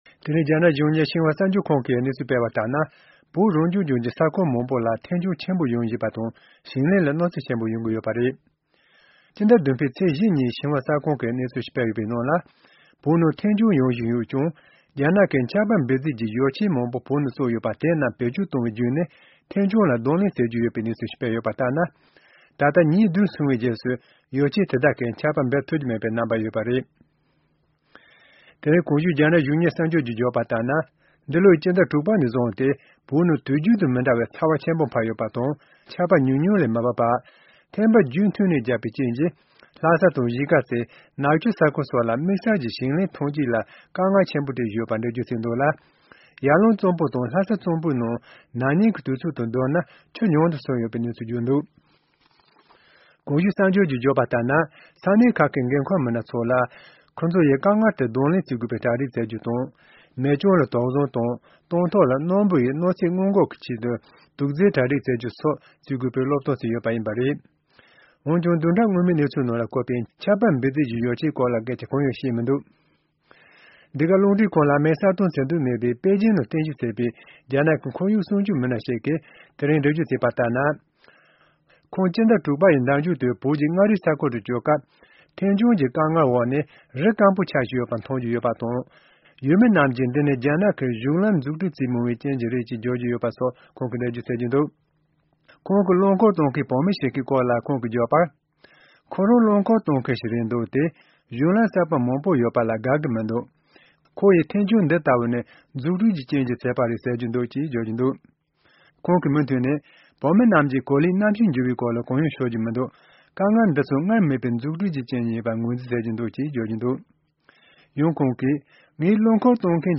འདི་ག་རླུང་འཕྲིན་ཁང་གིས་རྒྱ་ནག་གི་ཁོར་ཡུག་ཞིབ་འཇུག་པ་ཞིག་ལ་བཅར་འདྲི་ཞུས་ནས་ཤེས་རྟོགས་བྱུང་བ་ལྟར་ན་བོད་ཀྱི་ས་ཁུལ་ཁ་ཤས་ལ་རི་ལུང་སྐམ་པོར་གྱུར་ཏེ་བྱེ་ཐང་རྒྱ་སྐྱེད་འགྲོ་བཞིན་ཡོད་པའི་སྐོར།